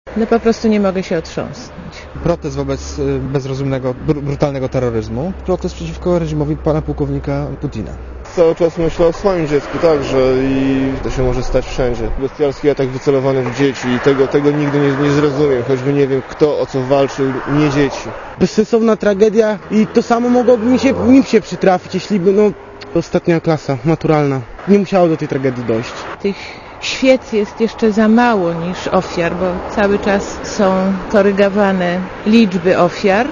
„Nie mogę się otrząsnąć”, „To protest przeciwko reżimowi Putina”, „To bezsensowna tragedia, zamach wymierzony w dzieci”, „To samo mogło mi się przydarzyć”, „Tych świec wciąż jest za mało...” – mówią warszawiacy, z którymi przed ambasadą Rosji rozmawiał Reporter Radia ZET.
Posłuchaj komentarzy warszawiaków